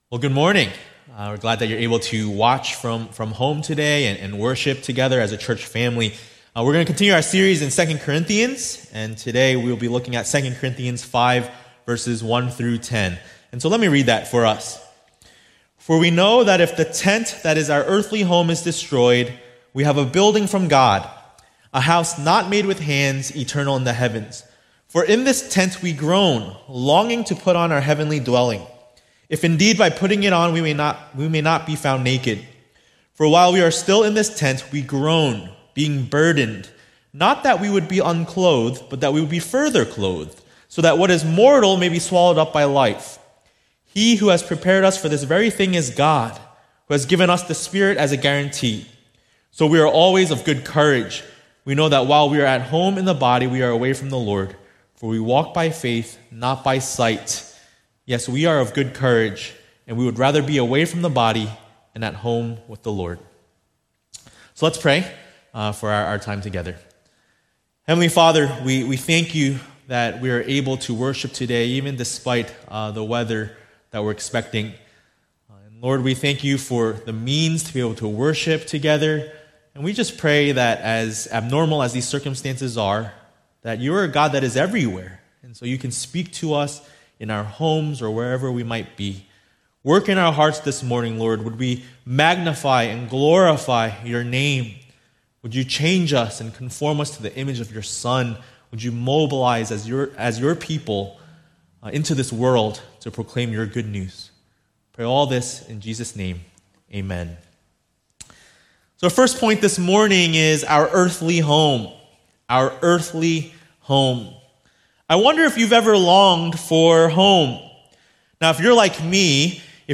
A message from the series "2 Corinthians ."